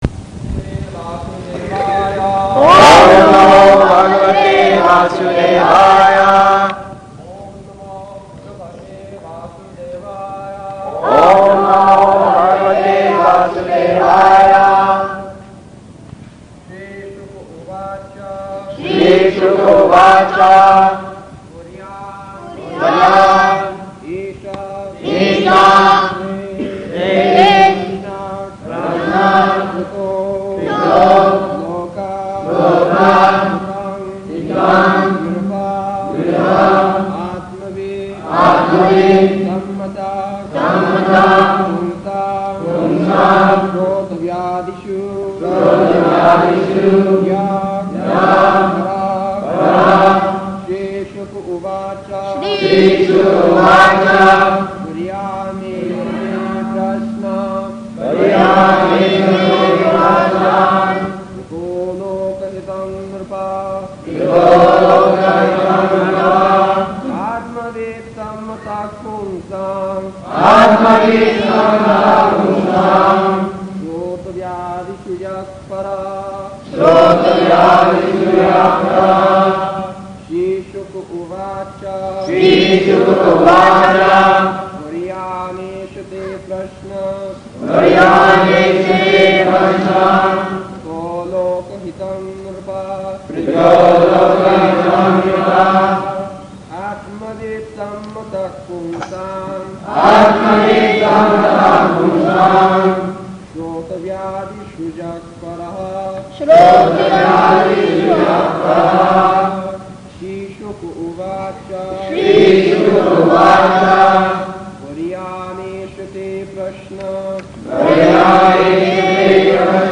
September 12th 1972 Location: Dallas Audio file
[Prabhupāda and devotees repeat]